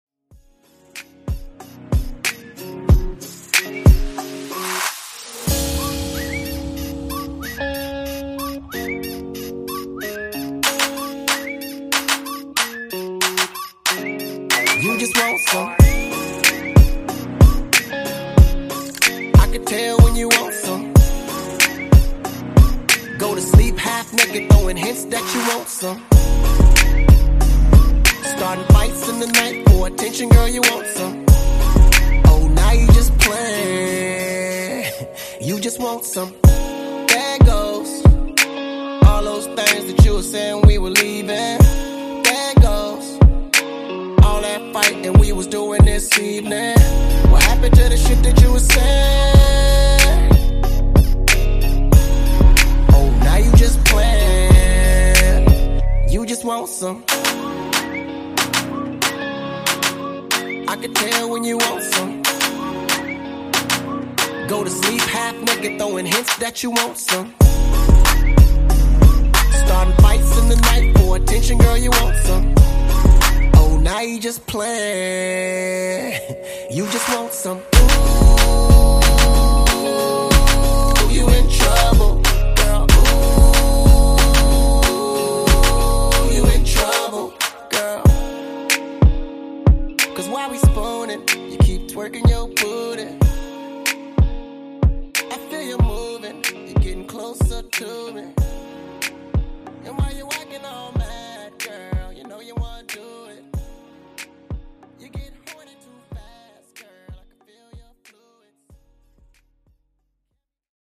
Genre: RE-DRUM Version: Dirty BPM: 88 Time